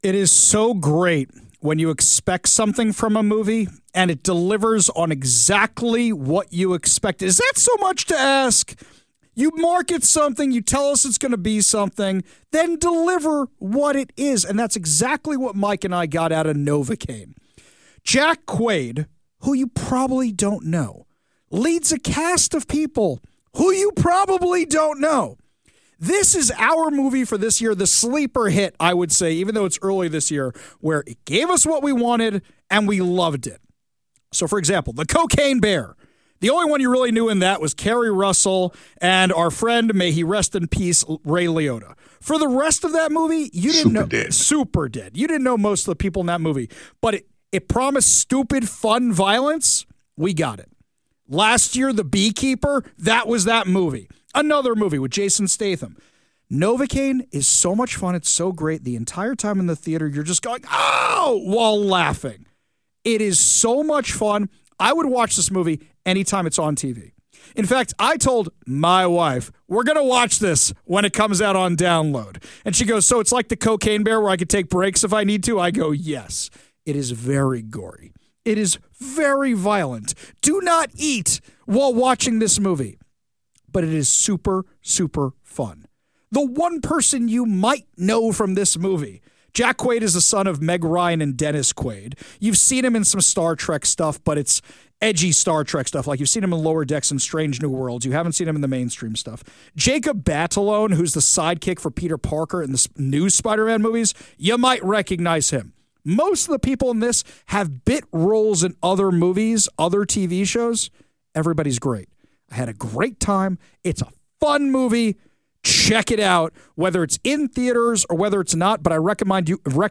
novocaine-review.mp3